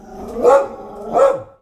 Download Free Dog Sound Effects | Gfx Sounds
Medium-size-dog-barking-2.mp3